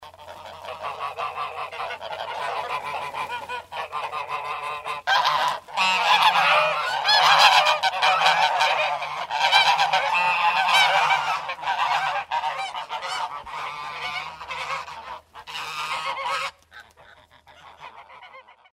На этой странице собраны звуки гусей – от привычного бормотания до громких криков.
Подборка включает голоса как домашних, так и диких гусей, обитающих у водоемов.